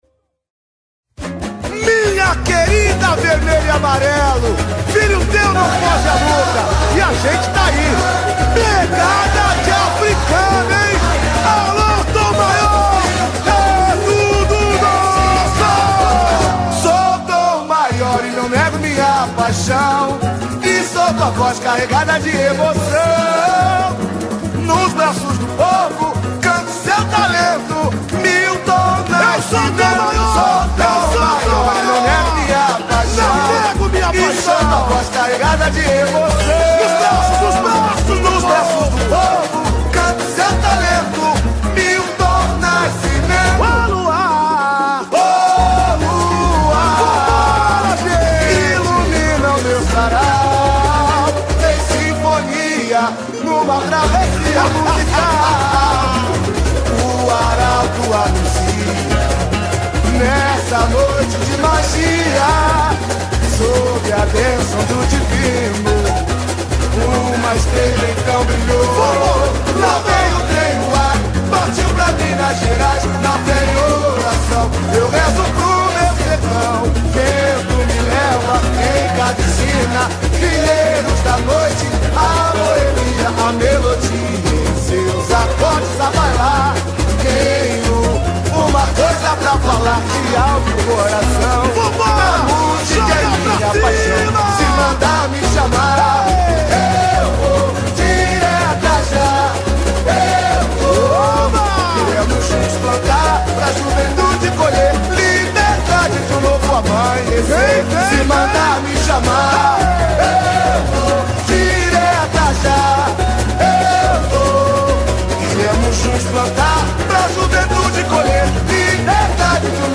Puxador: